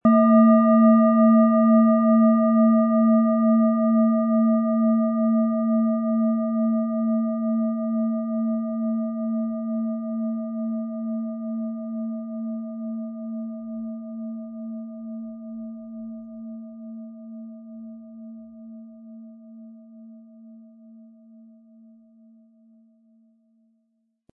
Antike Klangschalen - gesammelte Unikate
Die Oberfläche zeigt sanfte Spuren der Zeit - doch ihr Klang bleibt rein und klar.
• Tiefster Ton: Mond
Der Klang dieser Schale ist eine Mischung aus tiefer Berührung und klarer Helligkeit. Er entfaltet eine sanfte Wärme und lädt ein, sich in den Klang einzufühlen.
Im Audio-Player - Jetzt reinhören hören Sie genau den Original-Klang der angebotenen Schale. Wir haben versucht den Ton so authentisch wie machbar aufzunehmen, damit Sie gut wahrnehmen können, wie die Klangschale klingen wird.